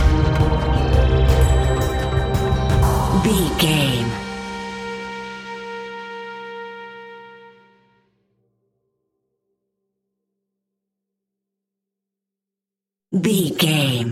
Aeolian/Minor
scary
ominous
dark
haunting
eerie
synthesizer
drum machine
ticking
electronic music
Horror Synths